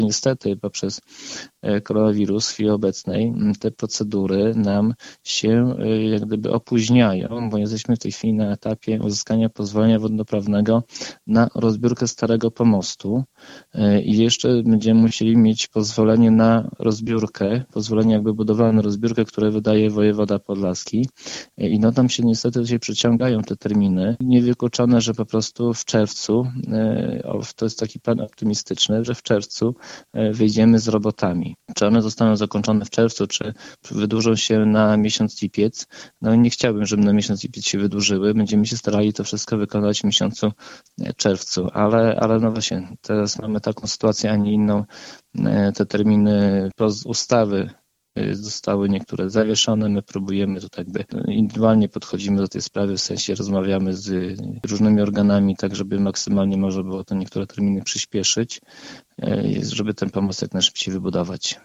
Pandemia utrudnia też przygotowanie samego kąpieliska w miejscowości Krzywe, gdzie gmina chce usunąć stary pomost i zbudować nowy. Niestety, jak mówi Zbigniew Mackiewicz, wójt gminy Suwałki, procedury przedłużają się i jest ryzyko, że prace przeciągną się do lipca.